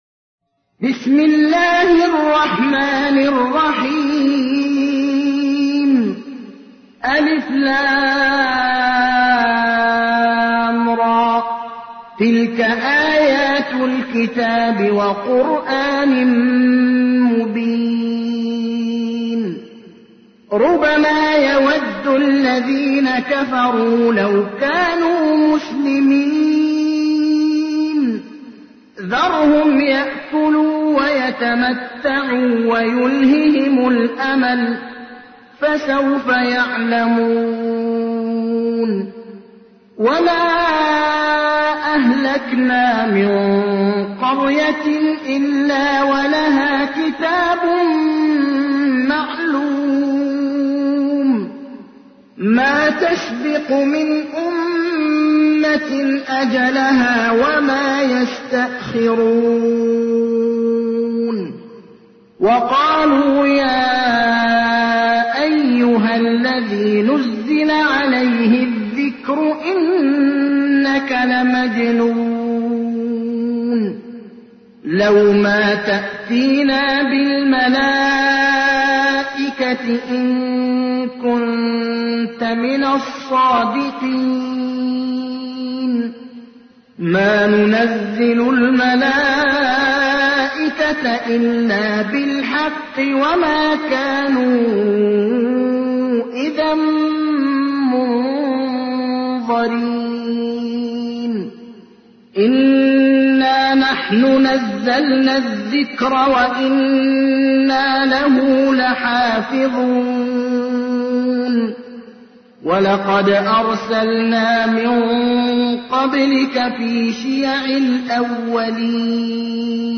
تحميل : 15. سورة الحجر / القارئ ابراهيم الأخضر / القرآن الكريم / موقع يا حسين